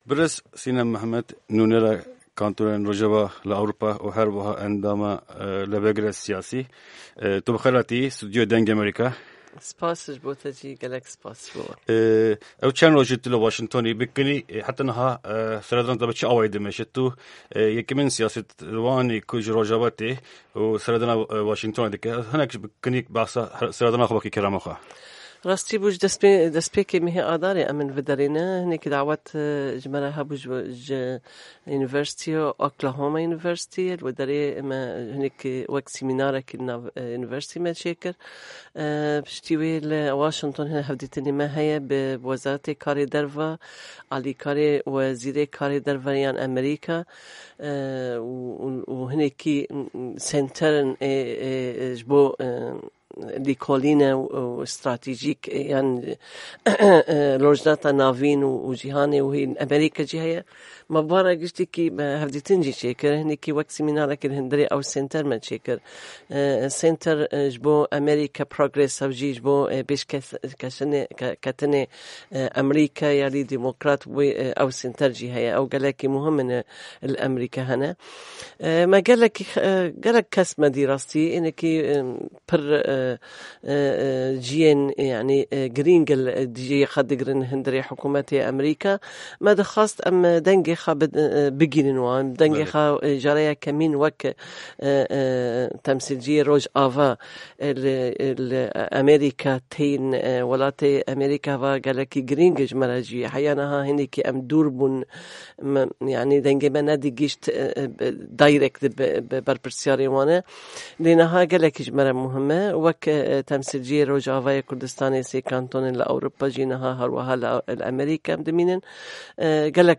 Hevpeyvîneke Taybet ligel Nûnera Kantonên Rojava Sînem Mihemed